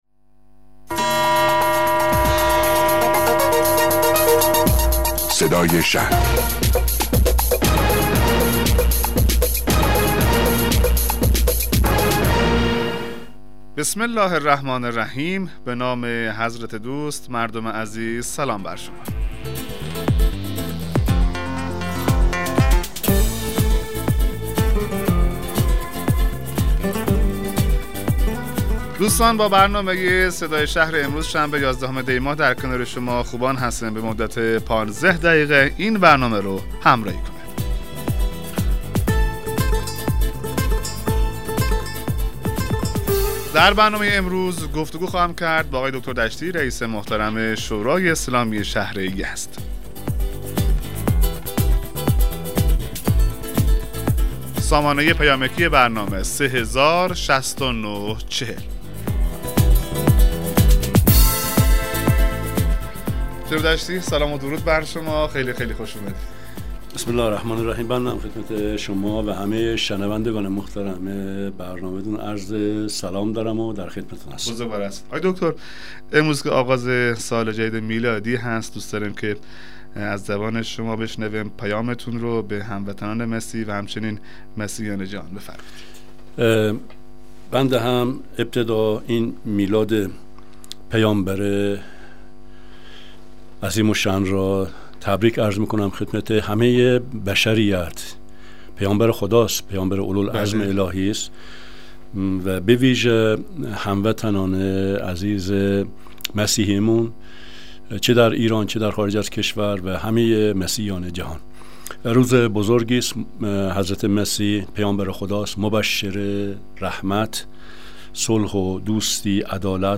مصاحبه رادیویی برنامه صدای شهر با حضور غلامحسین دشتی رییس شورای اسلامی شهر یزد